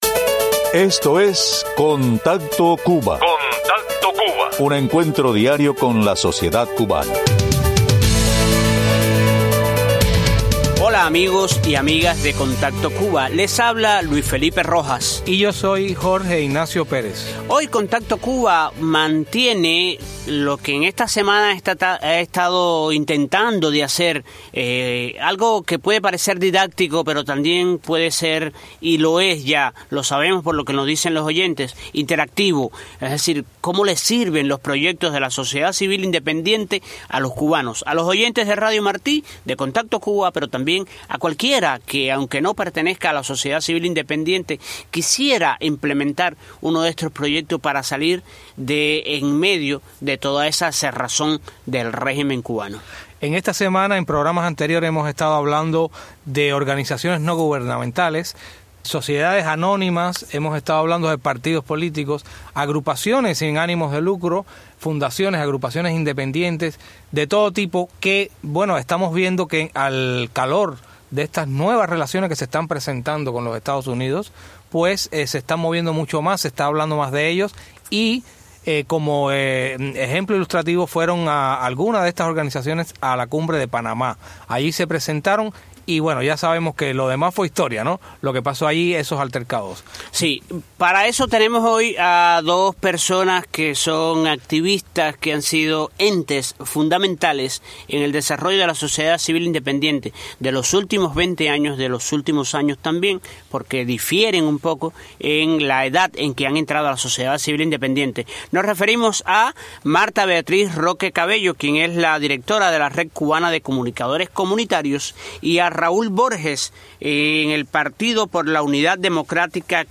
entrevistamos hoy a activistas y líderes de la oposición en la isla